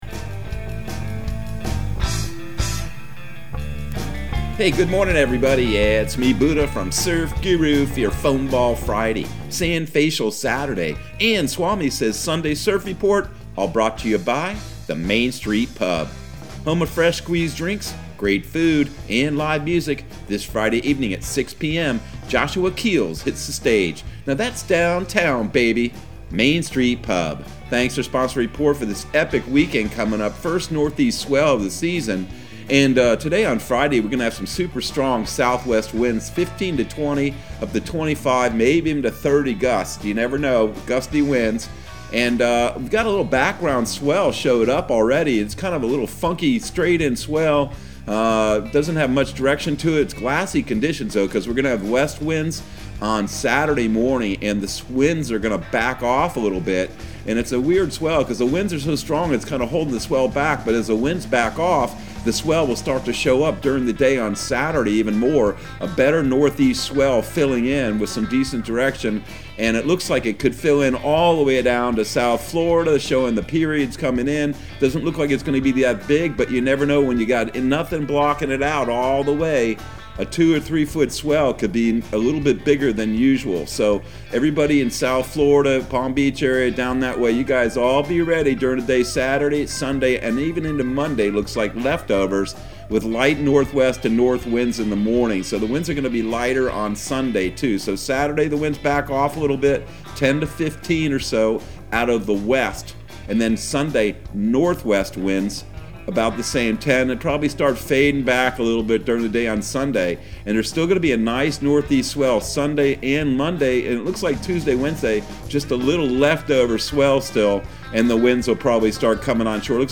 Surf Guru Surf Report and Forecast 10/29/2021 Audio surf report and surf forecast on October 29 for Central Florida and the Southeast.